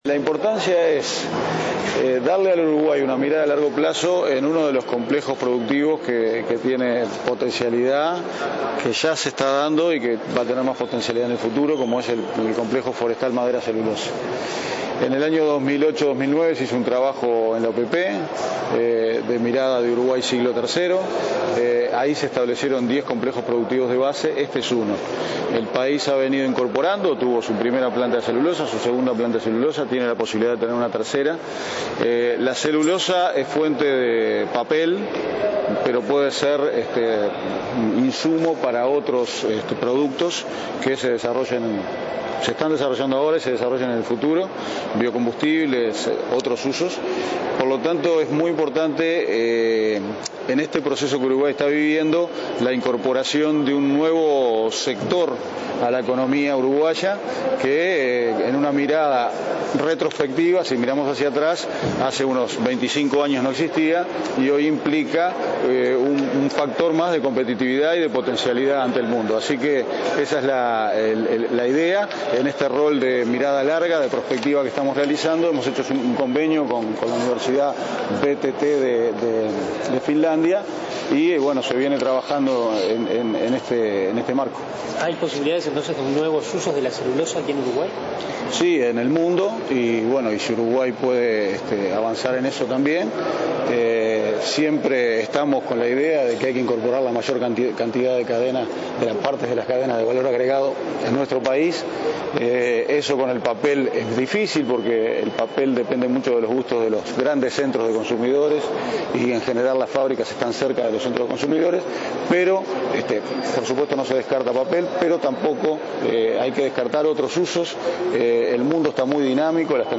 El Gobierno busca darle al país una mirada de largo plazo en uno de los complejos productivos con potencialidad de futuro como lo es el forestal-madera-celulosa”, señaló el director de OPP, Álvaro García en una conferencia realizada en Montevideo para analizar las perspectivas del sector junto con expertos finlandeses del Centro de Investigación Técnica, con quienes firmó un acuerdo para analizar nuevos usos de la celulosa.